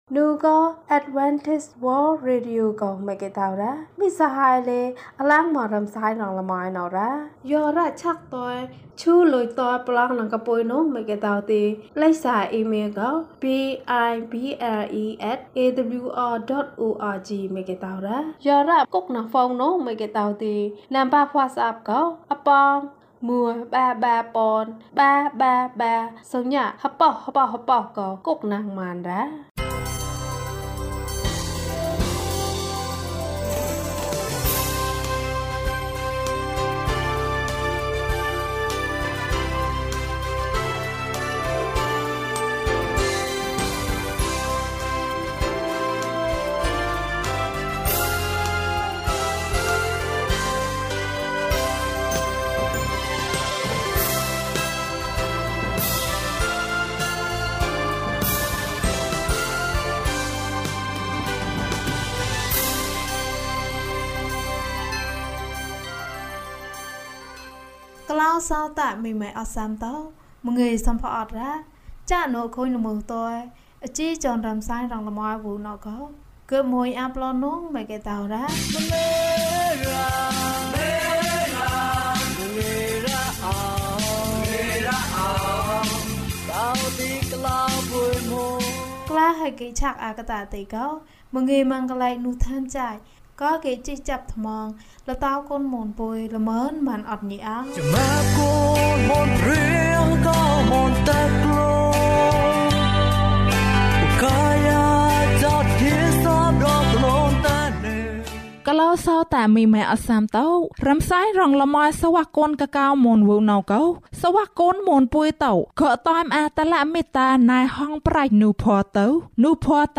ယေရှုသည် ကျွန်ုပ်၏အသက်ကို ကယ်တင်တော်မူ၏။ ကျန်းမာခြင်းအကြောင်းအရာ။ ဓမ္မသီချင်း။ တရားဒေသနာ။